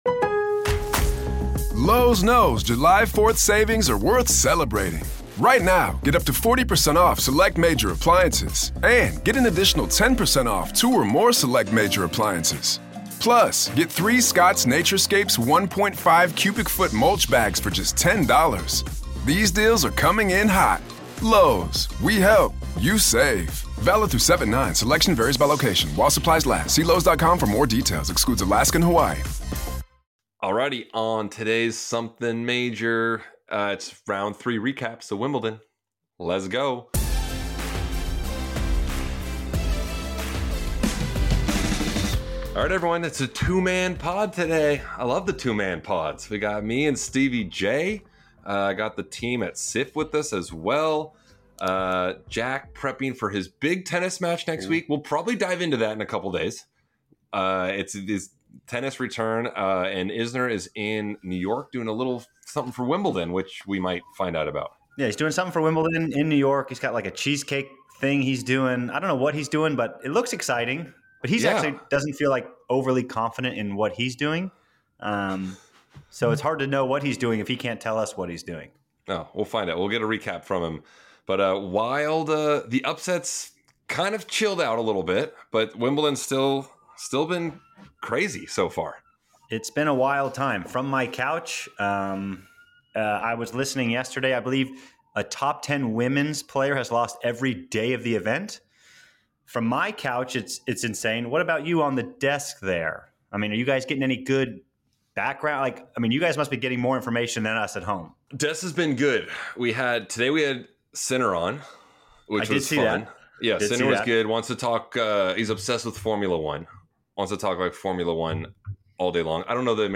two-man pod
It’s classic Something Major: tennis talk, personal stories, and plenty of laughs.